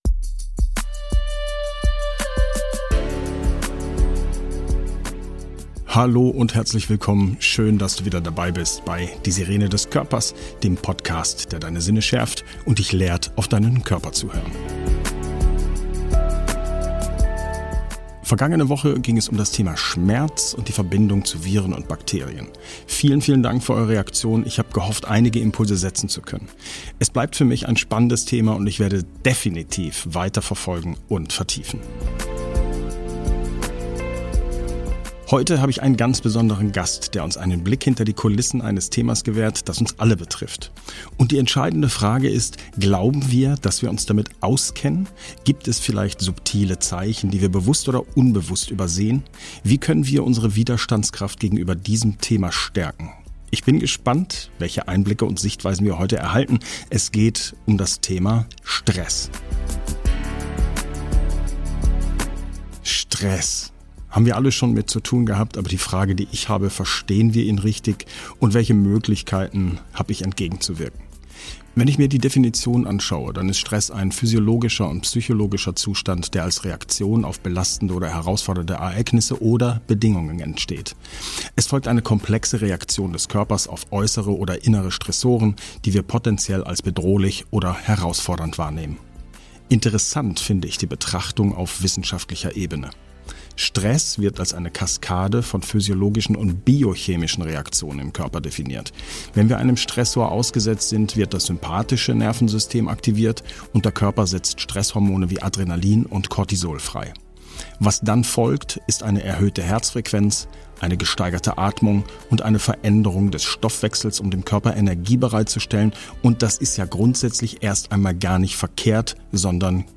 Dabei haben wir das Privileg, ein spannendes Experteninterview präsentieren zu dürfen. Unsere Expertin beantwortet herausfordernde Fragen und liefert wertvolle Einblicke in das Thema Stress.